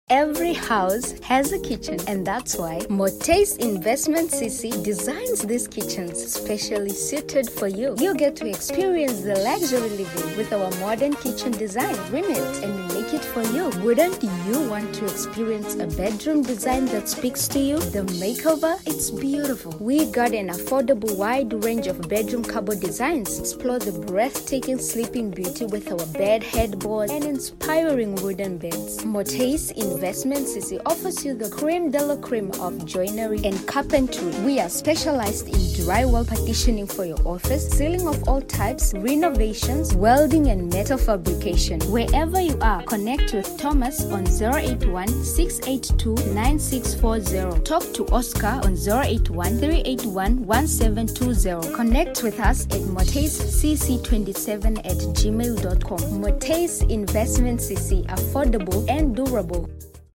Background Music - Omwene Gospel Instrumental